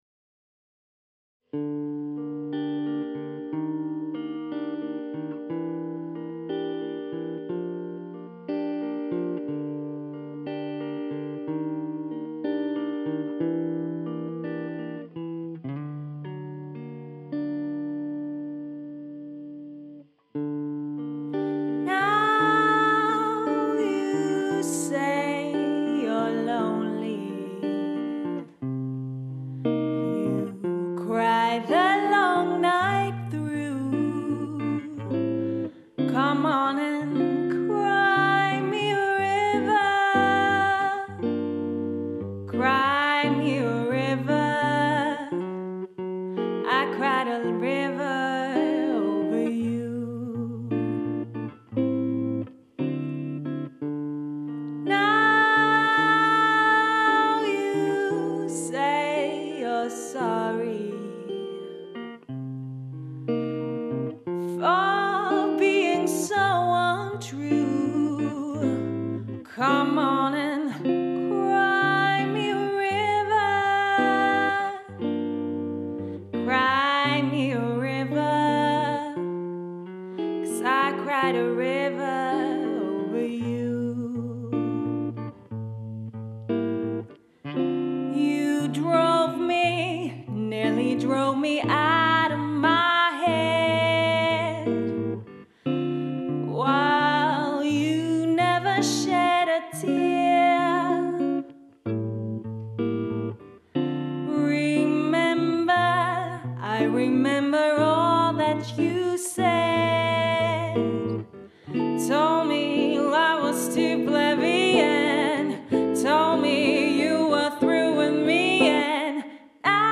Female Vocals, Acoustic Guitar